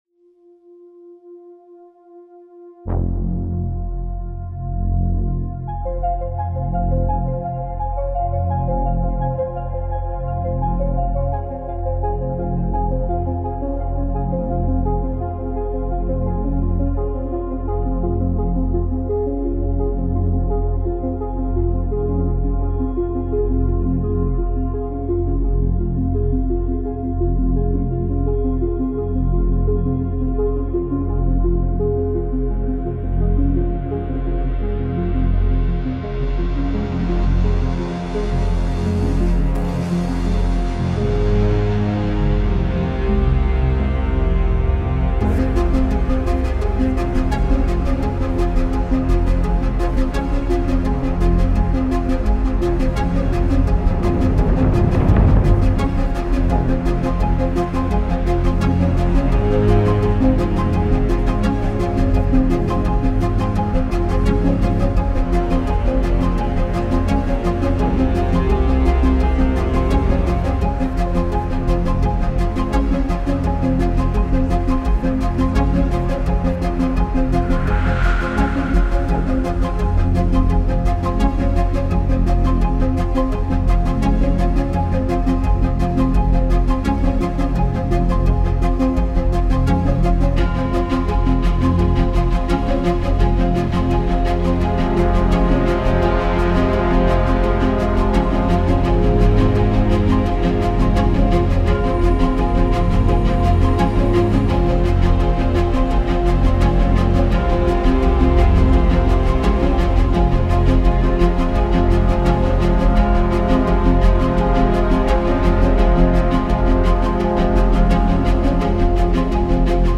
Composed Cinematic Soundtrack: The Final Cruelty